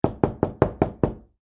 stuk-v-dver.ogg